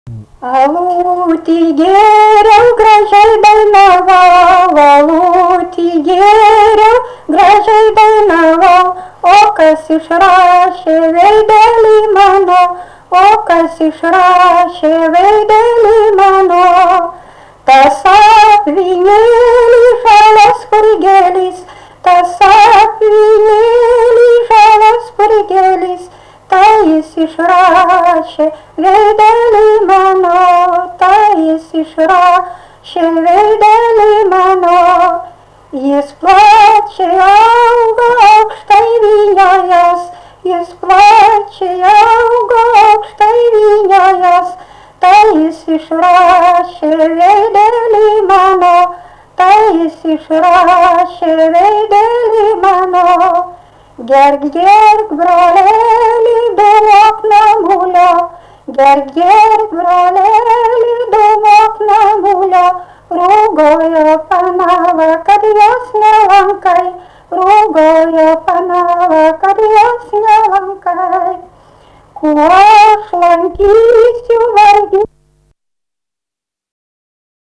daina, vestuvių
Erdvinė aprėptis Suvainiai
Atlikimo pubūdis vokalinis